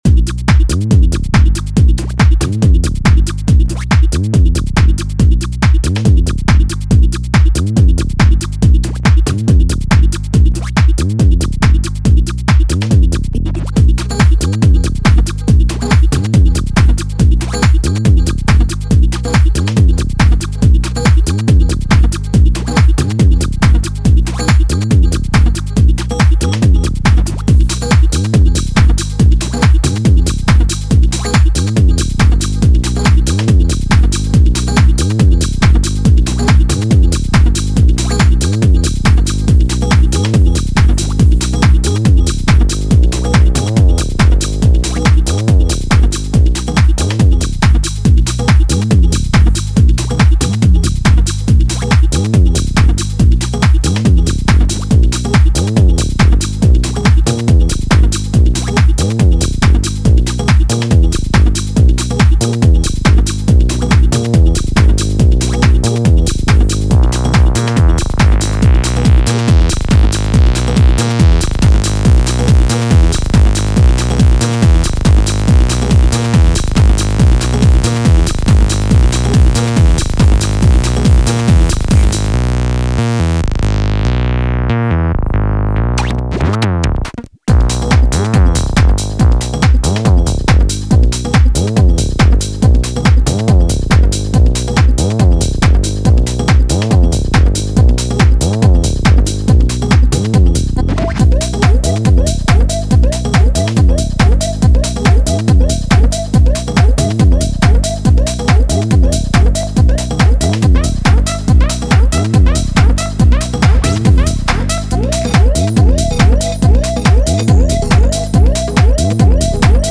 a collection of 4 tracks fine-tuned for the chaotic club.